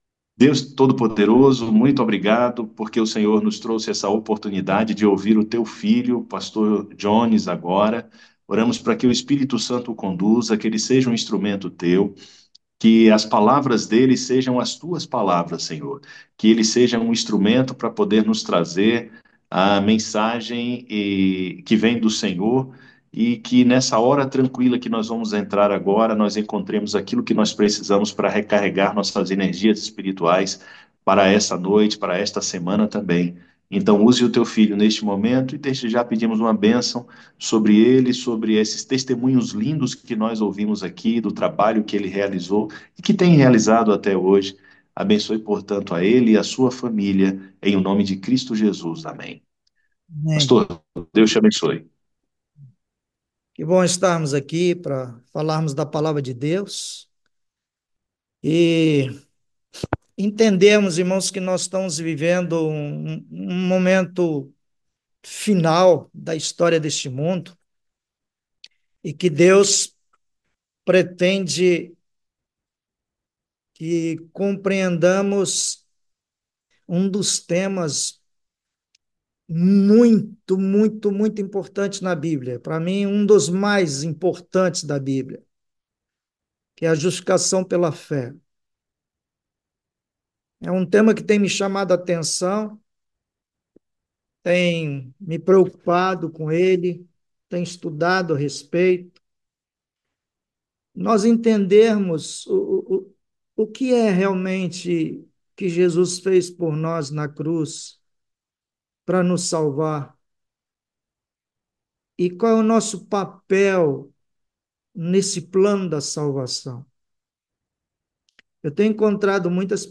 A Hora Tranquila é um devocional semanal.